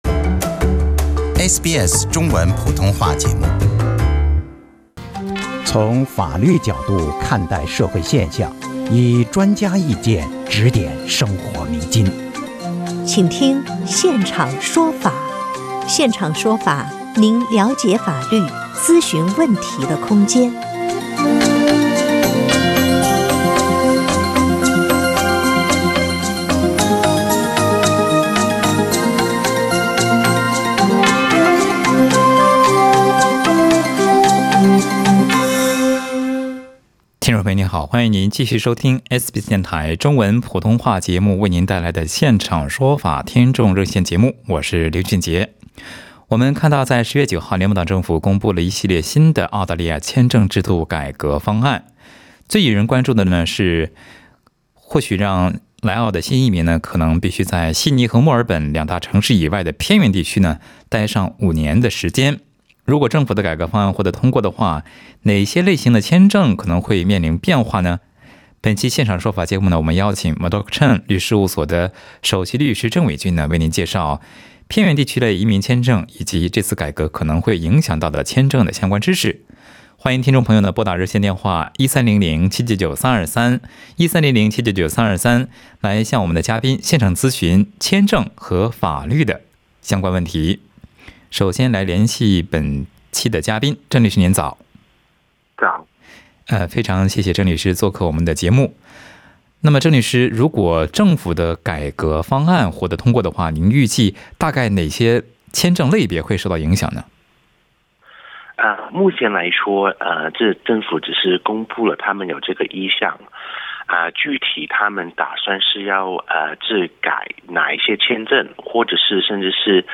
在本期《现场说法》听众热线节目中，一位女士打入电话称，因她的过桥签证即将过期，她面临离境和孩子分离的境况，咨询律师该怎么办。